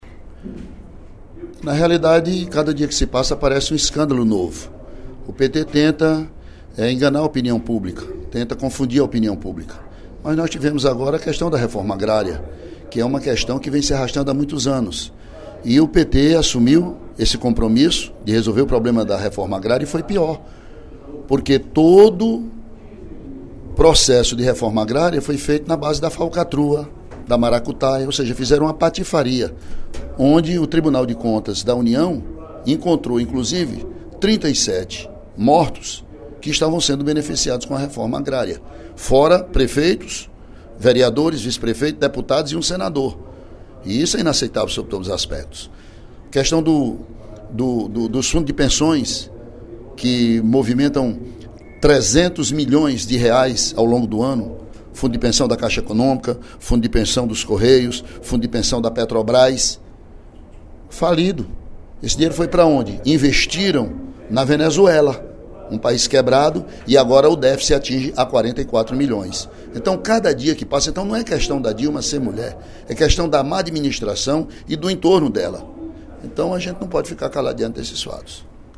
O deputado Ely Aguiar (PSDC) afirmou, no primeiro expediente da sessão plenária desta sexta-feira (08/04), que as reclamações e críticas da população em relação à presidente Dilma Rousseff "não se embasam no sexismo, e sim pela sua incompetência na condução do País".